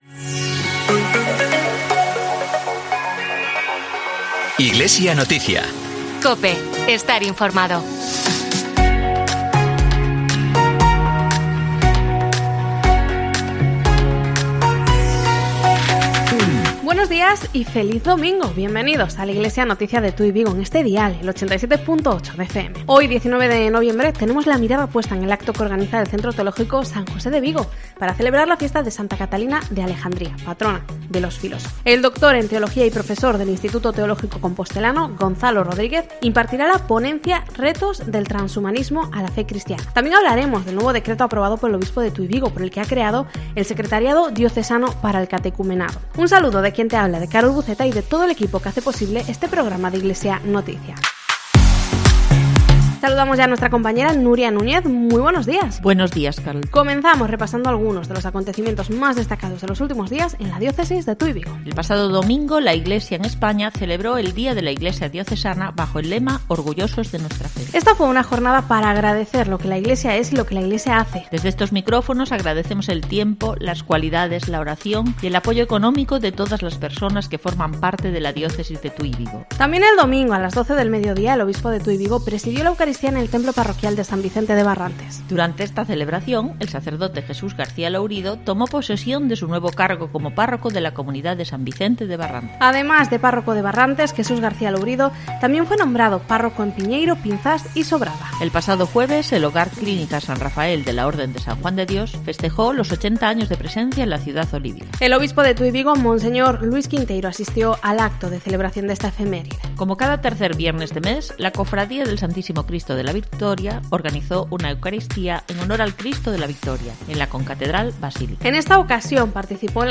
AUDIO: Informativo diocesano